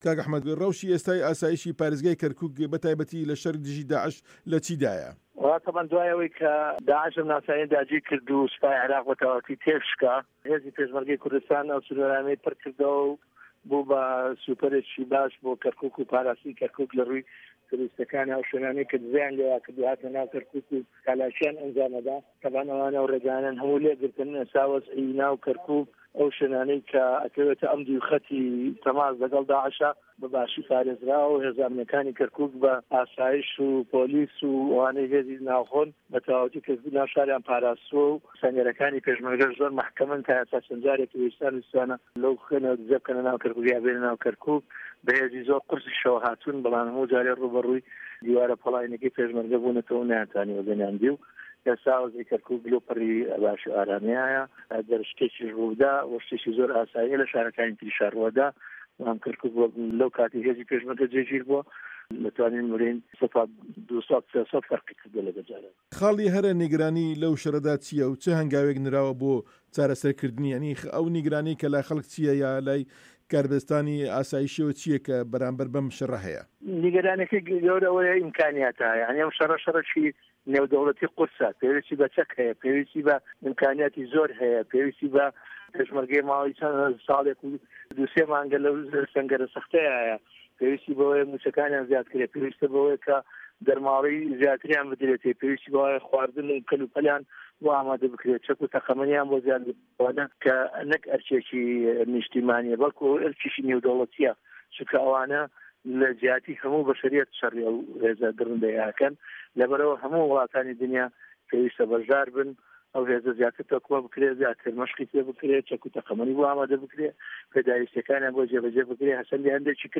وتووێژ لەگەڵ ئەحمەد عەسکەری